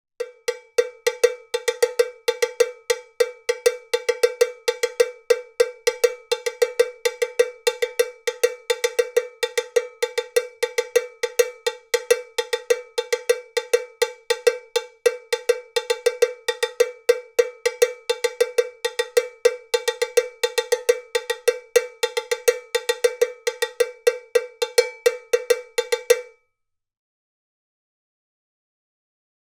MEINL Percussion Bongo Cowbell 8" - Black + Beater (BCOB+B)
The MEINL Bongo Cowbell is an 8" black powder-coated steel bell with a muffled sound for low-volume playing.